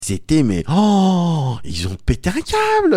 Tags: Cosy Corner Bruits de Bouches rires